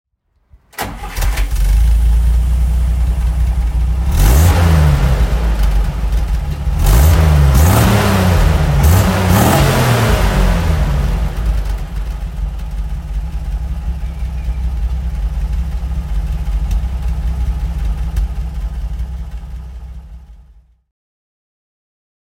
Nissan Patrol (1972) - Starten und Leerlauf